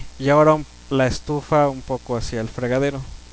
Ejemplo 2: Una elocución etiquetada con reparaciones de habla.